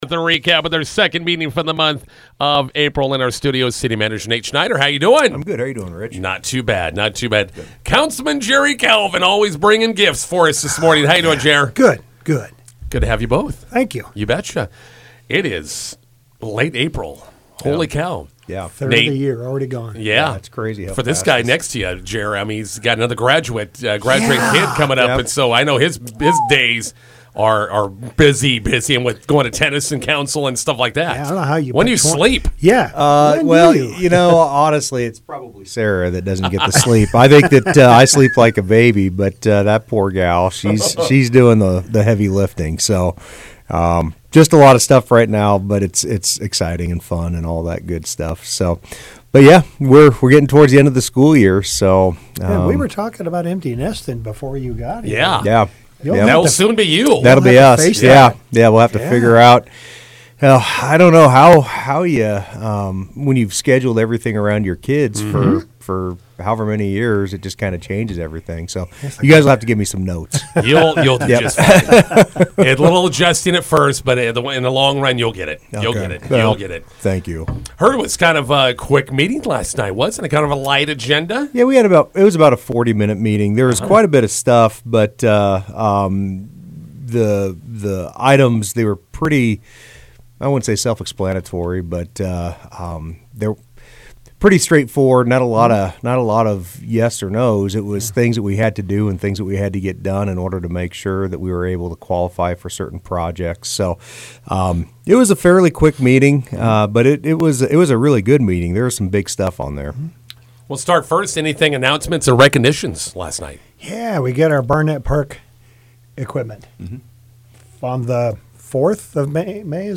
INTERVIEW: McCook City Council meeting recap with City Manager Nate Schneider and Councilman Jerry Calvin.